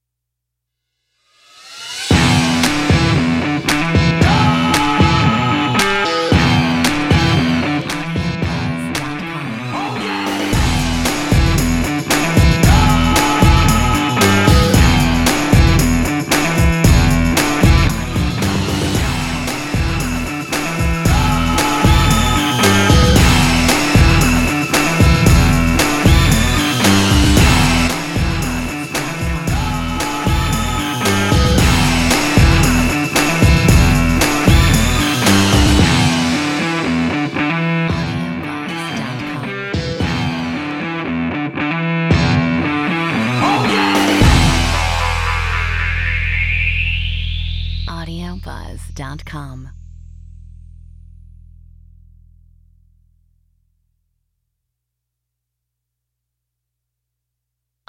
Metronome 115 BPM
Action / Sports Adventure / Discovery Aggressive Feel Good